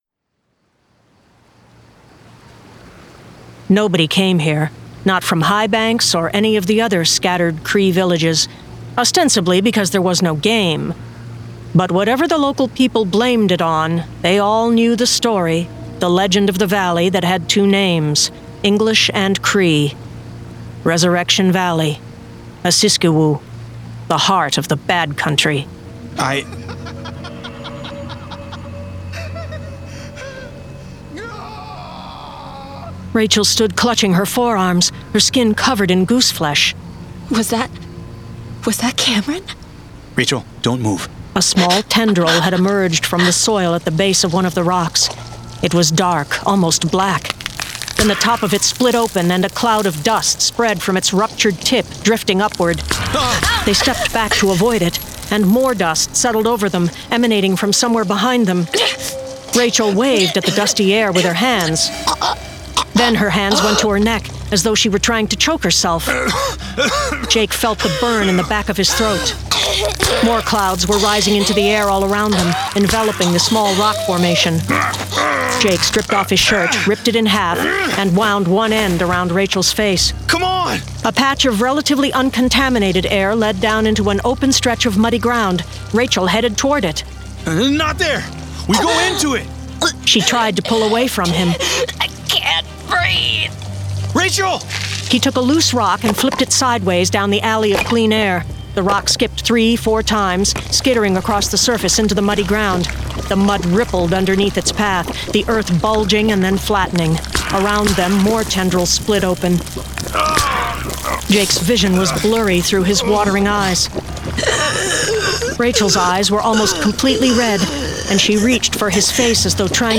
Full Cast. Cinematic Music. Sound Effects.
Genre: Horror